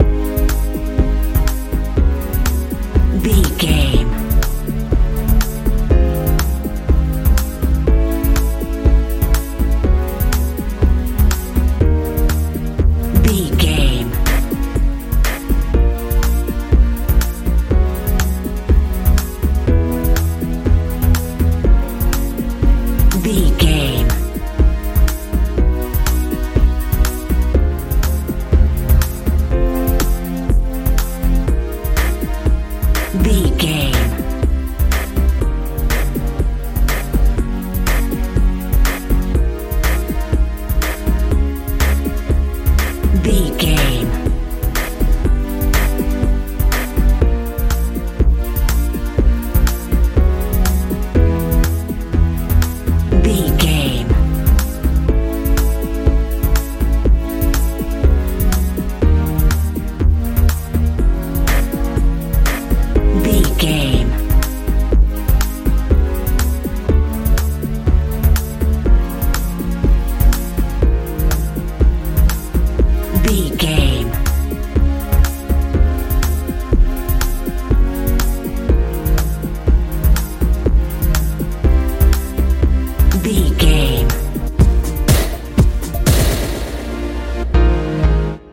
dance pop feel
Ionian/Major
G♯
magical
mystical
sleigh bells
piano
synthesiser
bass guitar
drums
90s